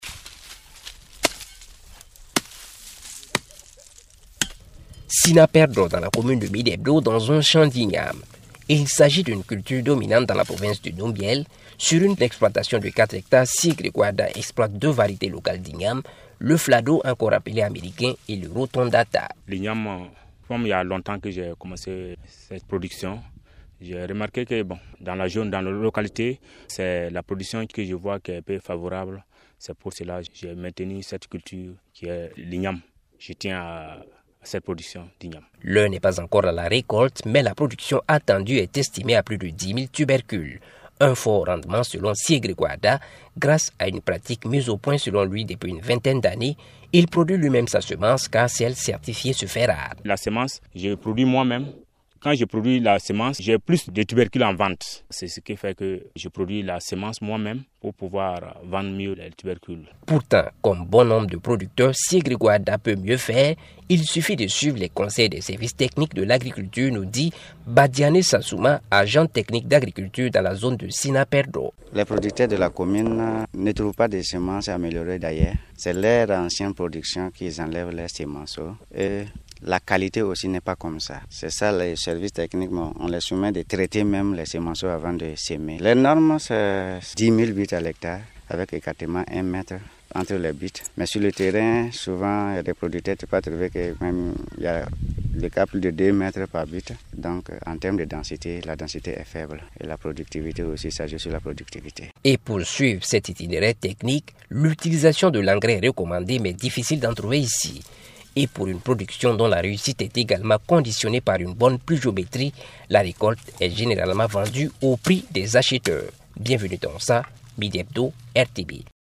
En effet, les producteurs ne respectent pas les itinéraires techniques définis par les services techniques de l’agriculture. Une de nos équipes s’est rendue dans les champs de Midebdo.